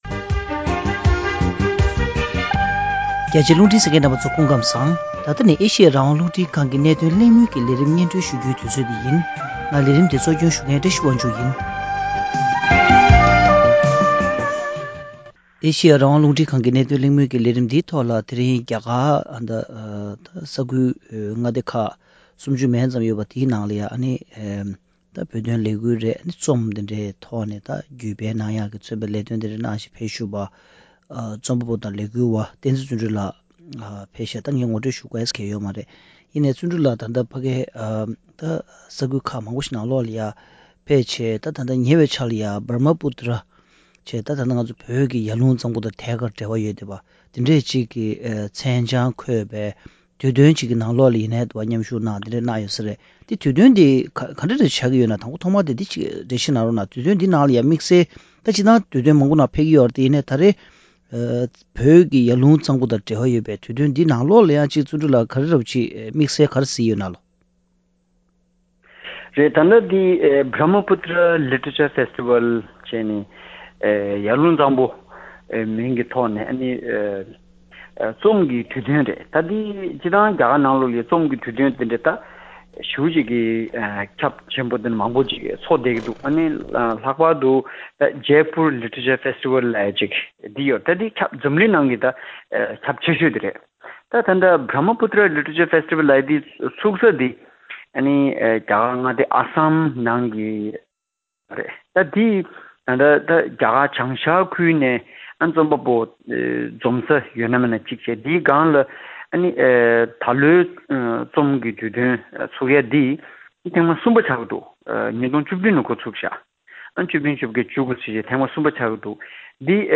རྒྱ་གར་བྱང་ཤར་ཨ་སམ་མངའ་ཁུལ་དུ་ཚོགས་པའི་ Brahmaputra རྩོམ་རིག་སྒྱུ་རྩལ་གྱི་དུས་སྟོན་ཐེངས་གསུམ་པའི་སྐབས་བོད་སྐོར་གླེང་སློང་ཇི་བྱུང་ཐད་གླེང་མོལ།